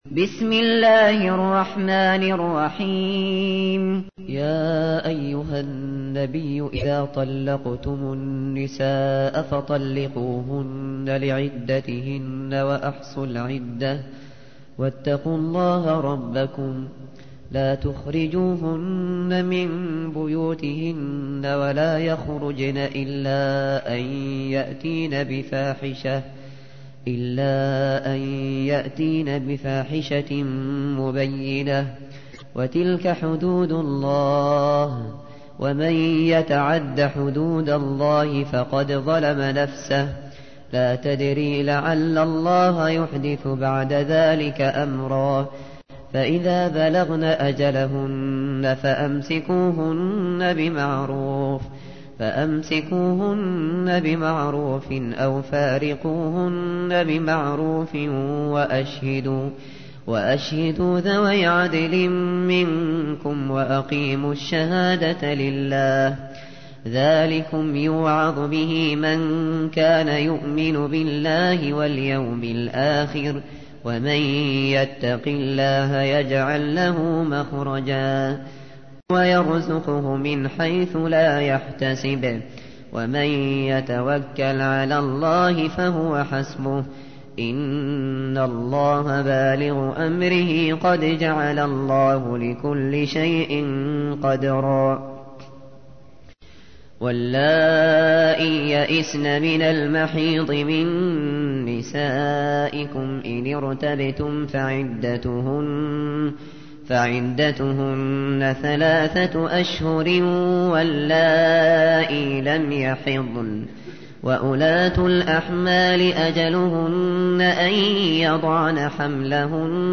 تحميل : 65. سورة الطلاق / القارئ الشاطري / القرآن الكريم / موقع يا حسين